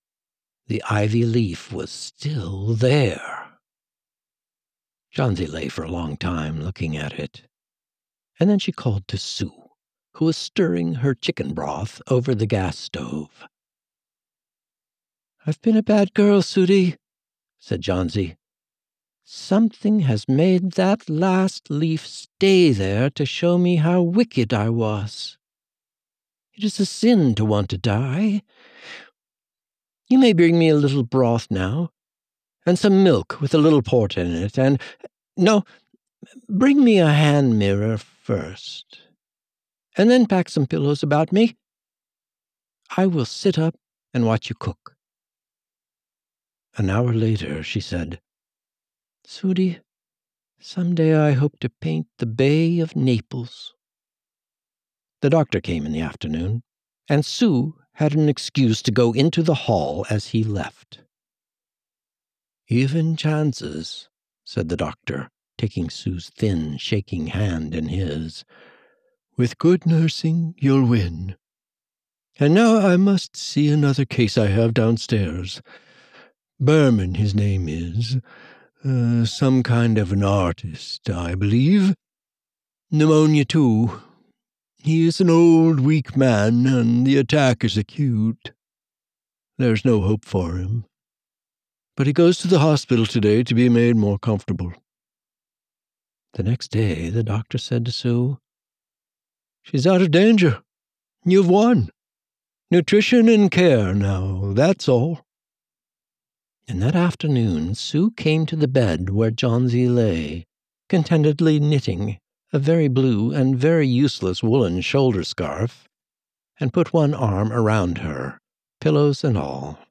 Audiobook Narrator